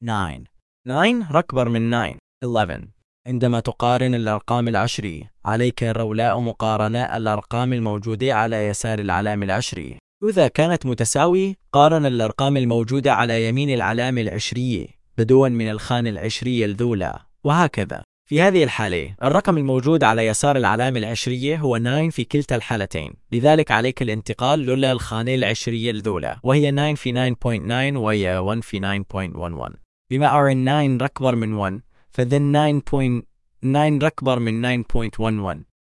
كانت لهجته تبدو سعودية.